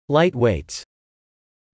Spelling: /ˈtred.mɪl/